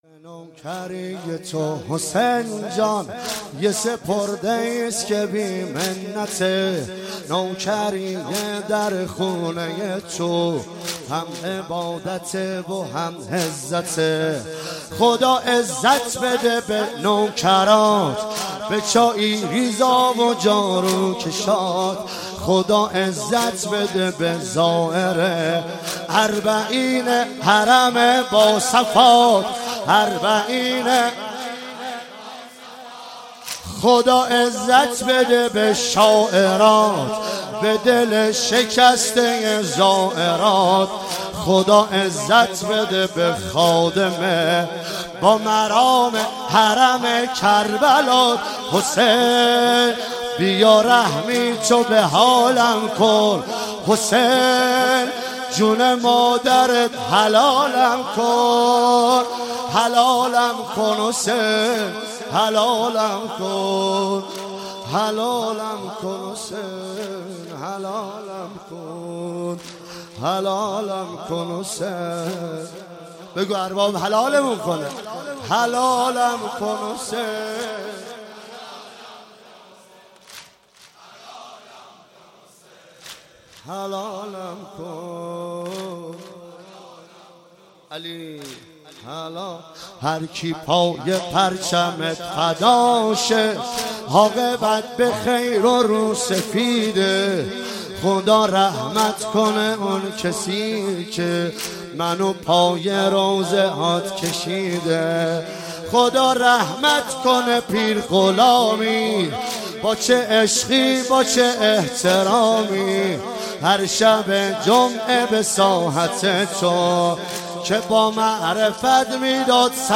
واحد مداحی